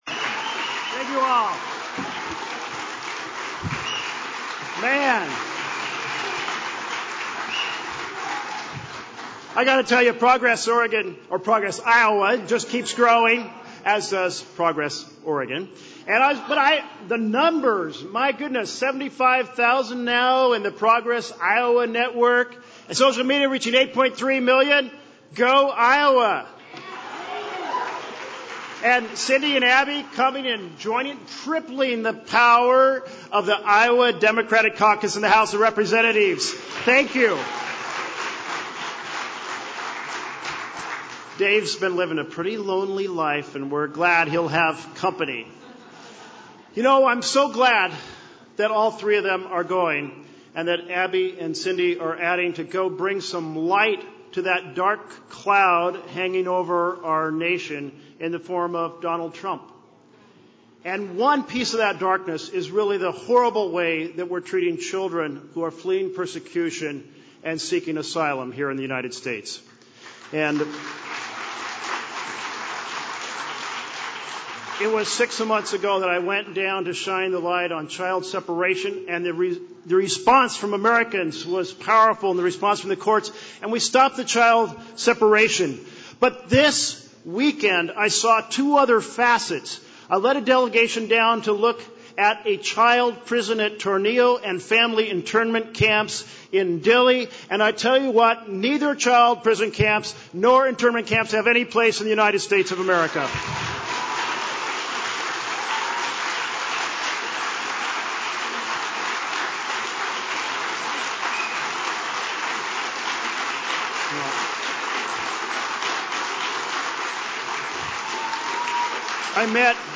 AUDIO of Merkley’s speech, 11:00 Andrew Yang, a New York businessman, is the only one of the four who is an officially declared candidate for president, with paid staff already working in Iowa.
AUDIO of Yang’s speech, 10:45 Tonight’s event was an annual holiday party organized by “Progress Iowa” and the group kicked things off with speeches from the two Democrats who will be the first women to representing Iowa in congress.